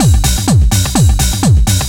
DS 126-BPM A09.wav